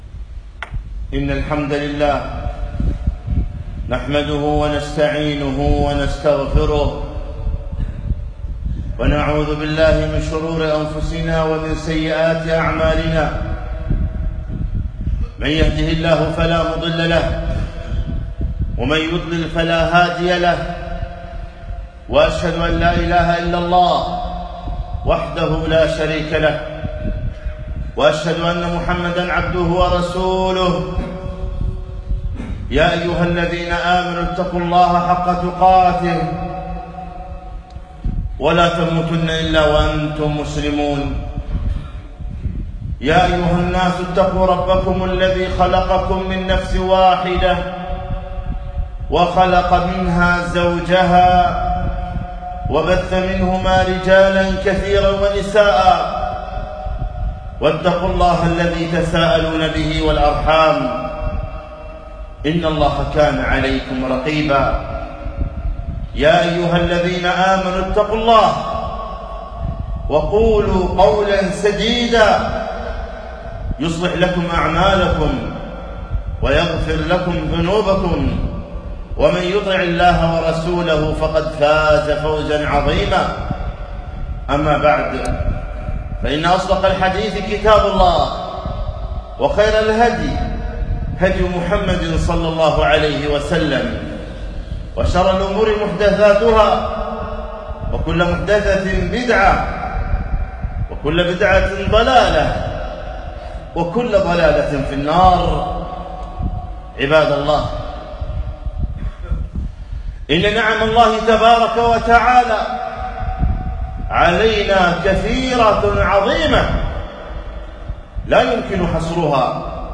خطبة - هل شكرتم نعم الله ؟